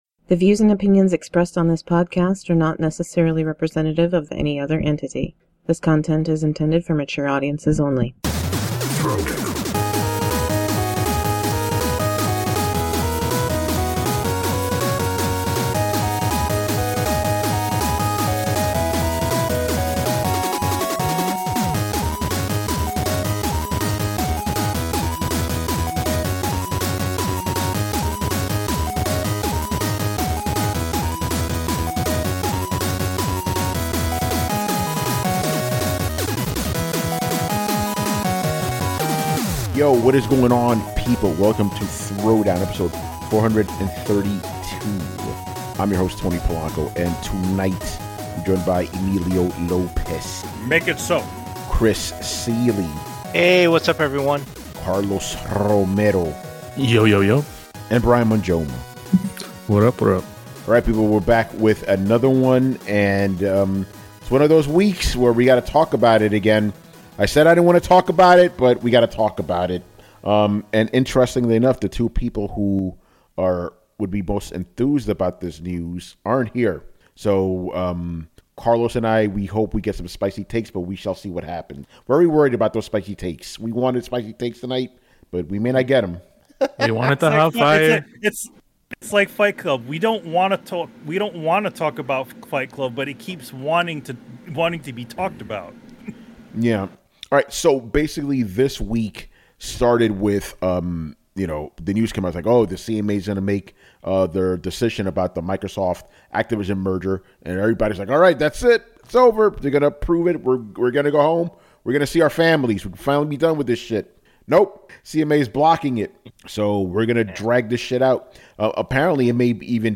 our intro and outro music.